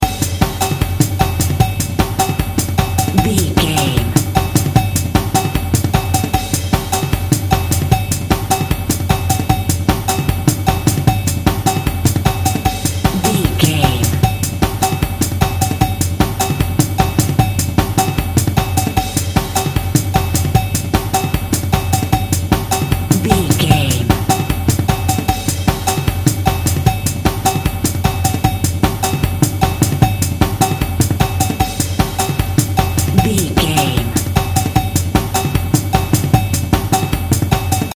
Epic / Action
Fast paced
Atonal
Fast
intense
energetic
percussion
Drum and bass
break beat
electronic
sub bass
synth drums
synth leads
synth bass